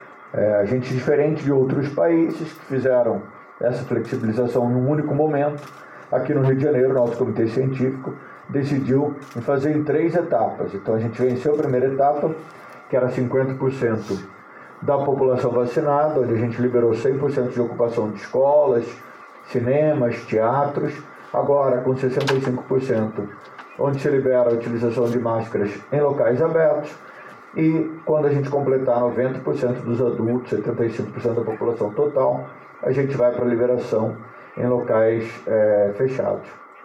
O Secretário municipal de saúde, Daniel Soranz,  durante a divulgação do boletim epidemiológico, nesta sexta-feira, comparou a flexibilização no Rio de Janeiro com as de outros países que não as fizeram por etapas.